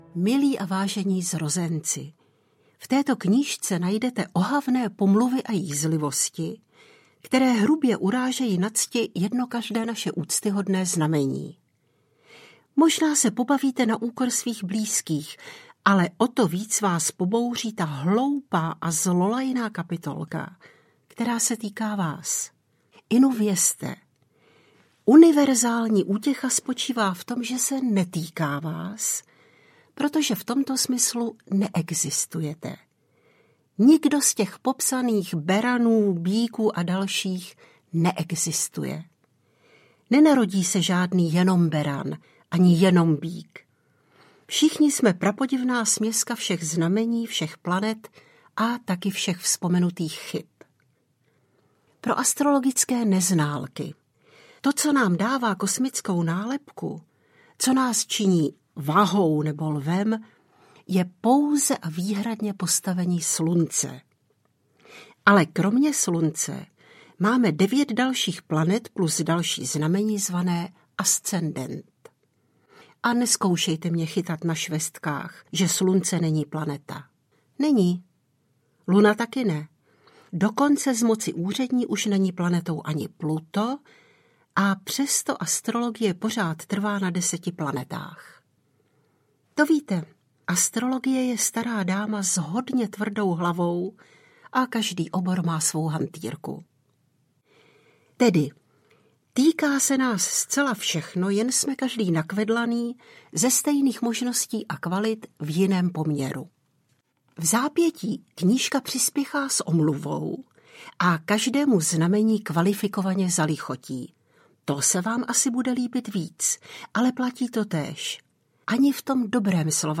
Pikantní astrologie audiokniha
Ukázka z knihy